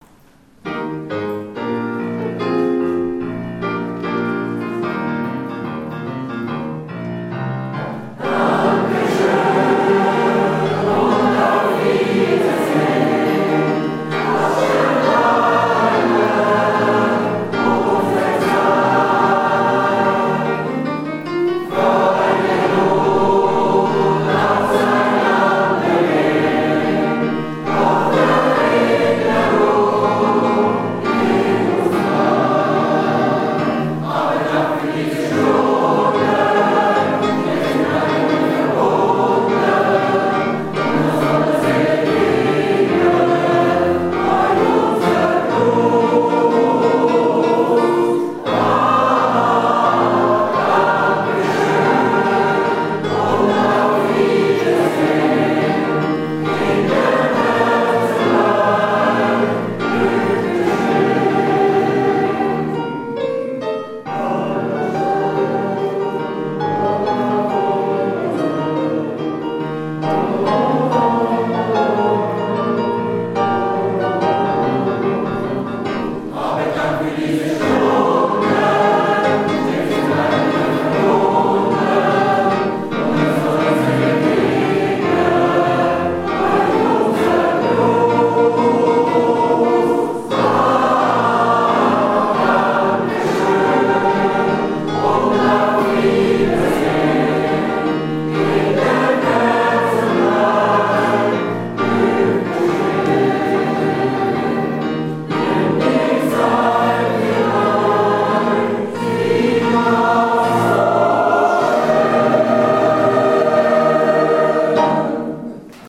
April 2018   reformierte Kirche Glattfelden
Live-Aufzeichnung des Konzertes
Gesamtchor,